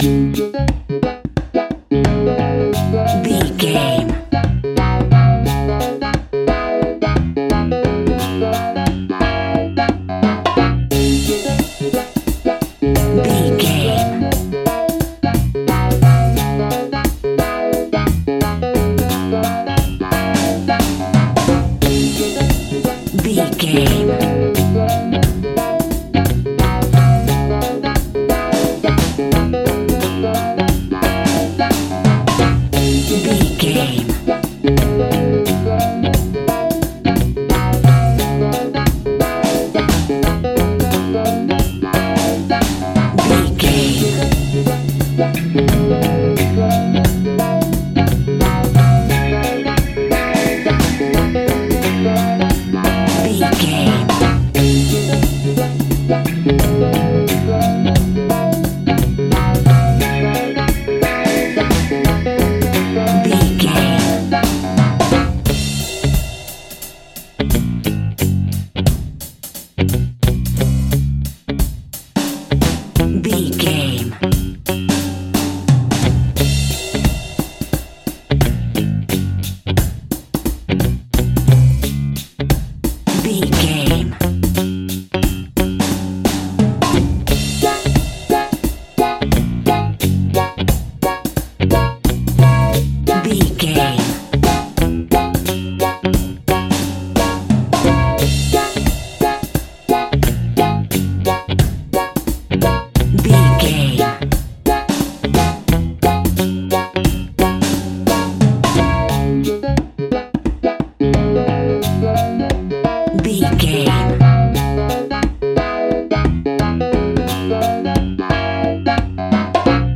A groovy and funky piece of classic reggae music.
Aeolian/Minor
D♭
dub
laid back
chilled
off beat
drums
skank guitar
hammond organ
transistor guitar
percussion
horns